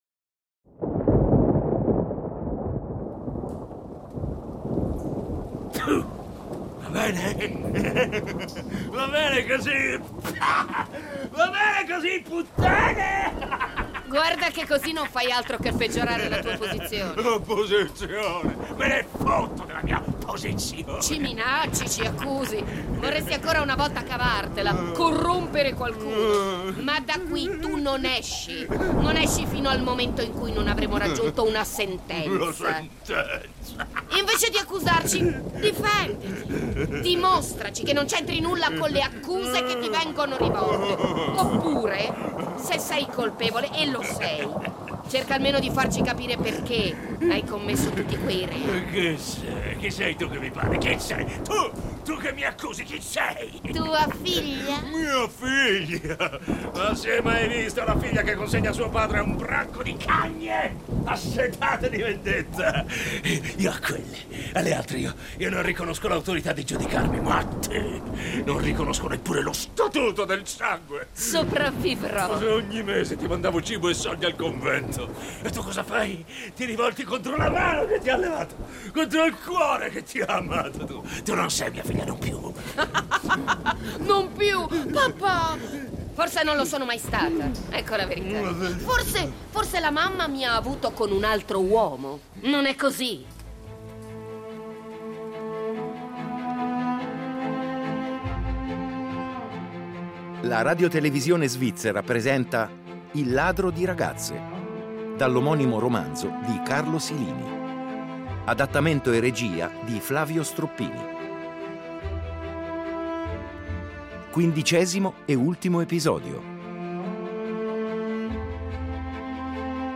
Un “film per le orecchie” che in un crescendo di continui colpi di scena, tradimenti, omicidi e con un sound design d’eccellenza, racconta dell’epica resistenza degli ultimi e della loro vendetta.